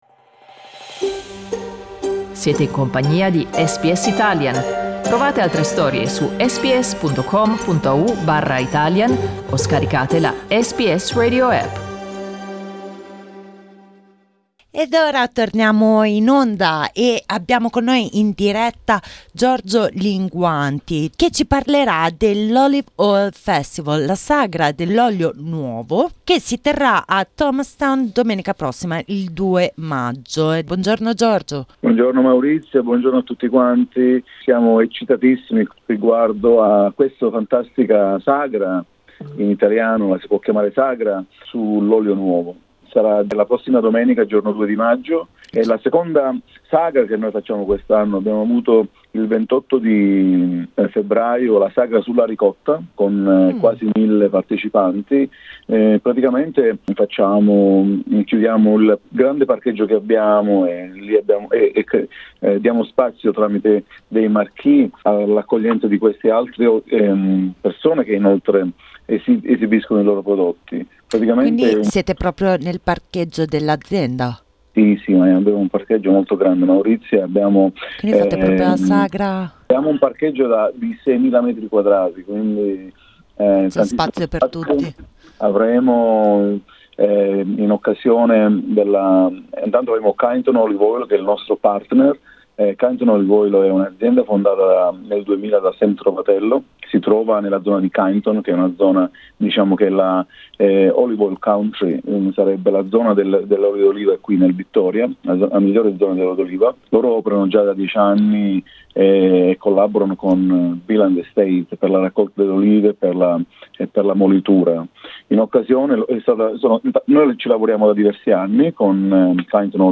ha presentato il programma dell'evento ai microfoni di SBS Italian .